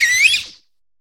Cri de Zapétrel dans Pokémon HOME.